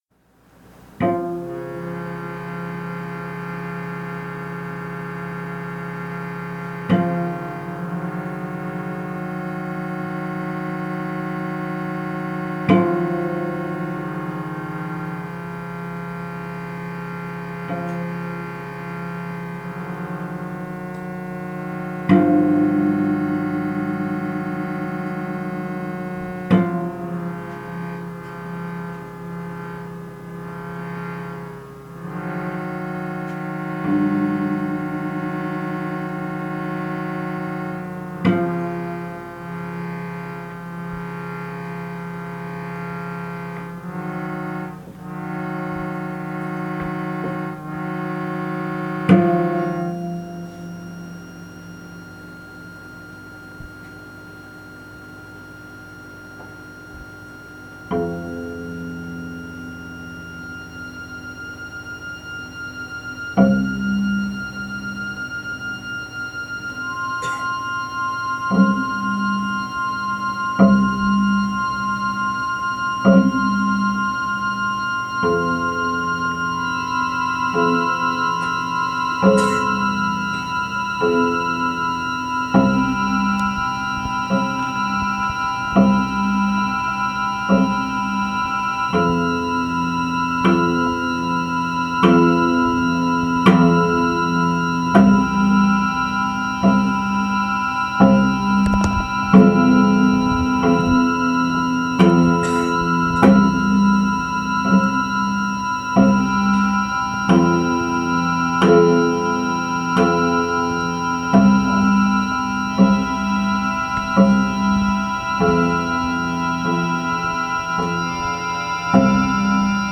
Audio captured during the performance of "Malou
The performance featured costumes, live music, and a short film.